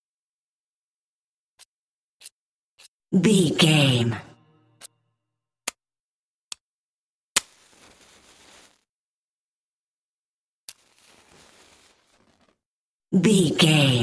Matches
Sound Effects
fire